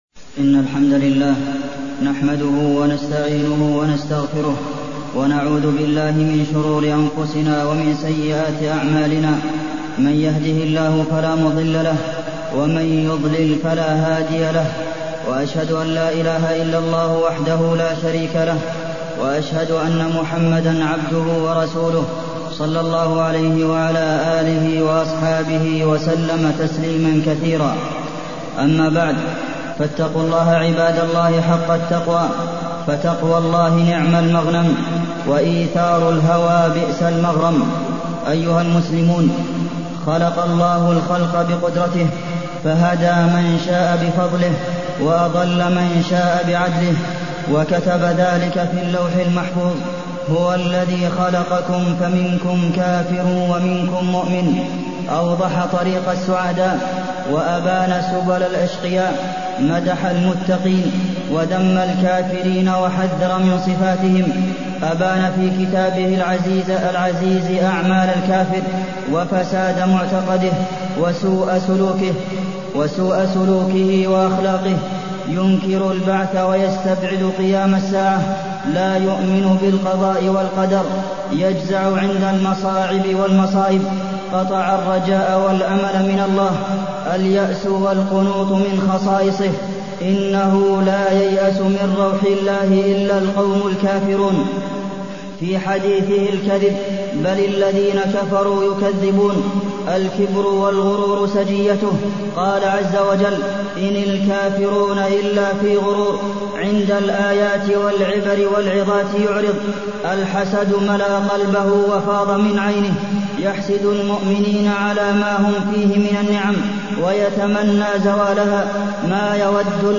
تاريخ النشر ٢٤ صفر ١٤٢٢ هـ المكان: المسجد النبوي الشيخ: فضيلة الشيخ د. عبدالمحسن بن محمد القاسم فضيلة الشيخ د. عبدالمحسن بن محمد القاسم الكفر وأهله